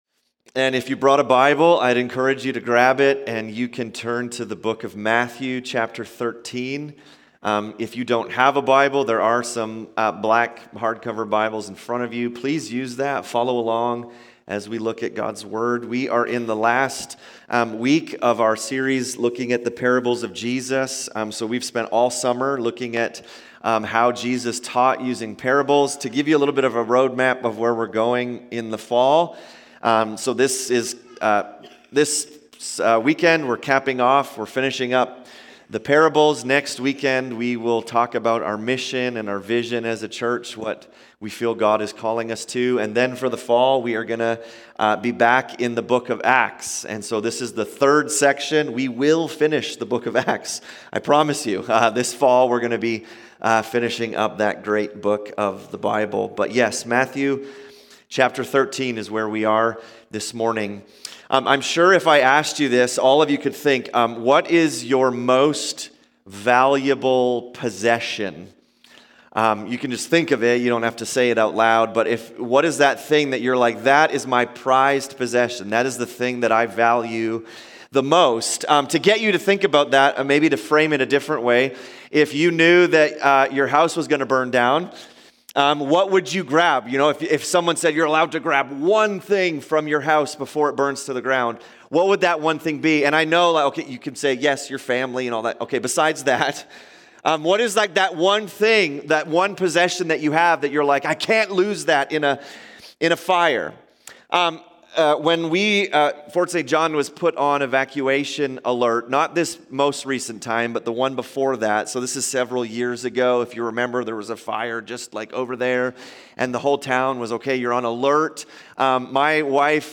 Subscribe via iTunes to our weekly Sermons